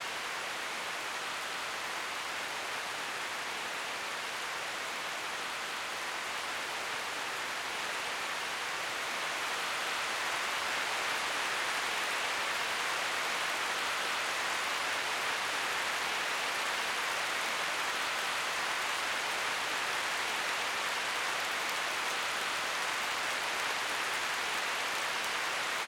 BOOM Library presenta RAIN, il plug-in sound designer pensato per replicare il suono della pioggia nelle sue varie sfumature.
RAIN_DreamyFlora.mp3